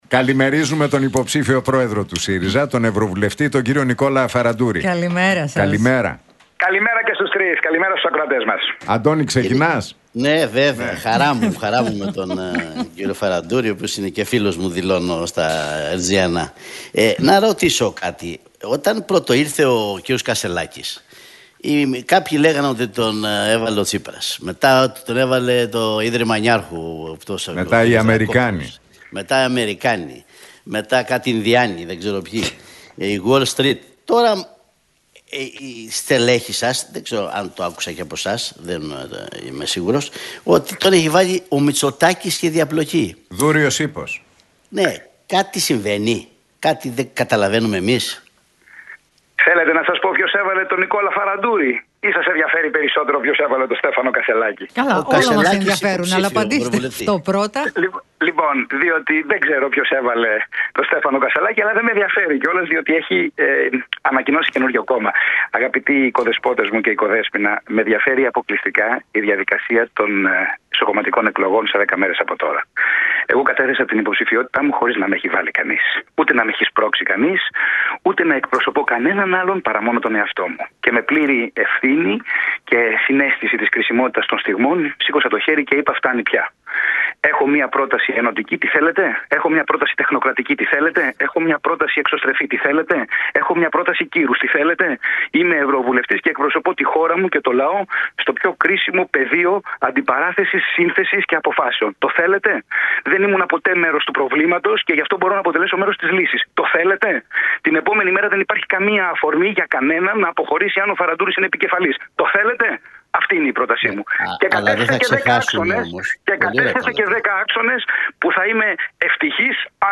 Φαραντούρης στον Realfm 97,8: Προτείνω καταστατικό συνέδριο για αλλαγή και δύο αντιπροέδρους στον ΣΥΡΙΖΑ
Για τις εσωκομματικές εξελίξεις στον ΣΥΡΙΖΑ και την υποψηφιότητα του για την προεδρία μίλησε, μεταξύ άλλων, ο ευρωβουλευτής του ΣΥΡΙΖΑ, Νικόλας Φαραντούρης